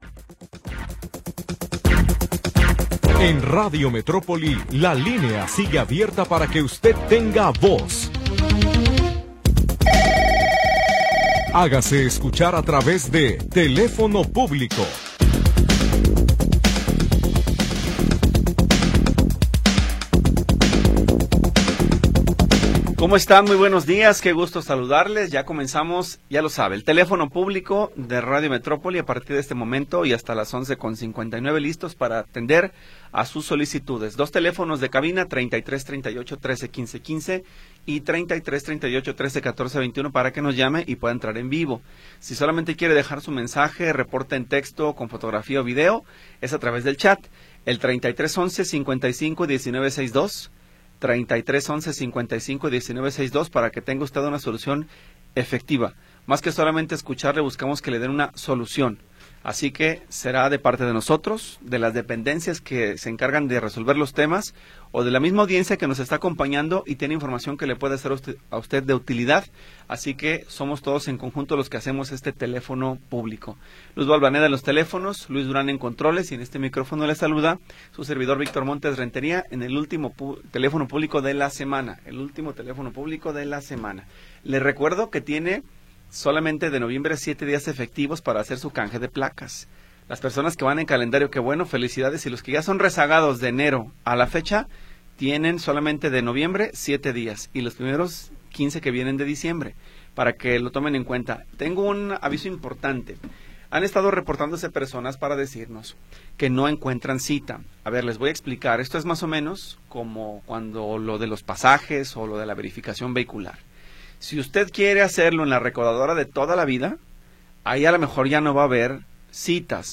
Programa transmitido el 21 de Noviembre de 2025.